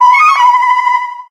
Audio / SE / Cries / MELOETTA_P.ogg